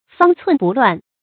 方寸不乱 fāng cùn bù luàn 成语解释 方寸：指心。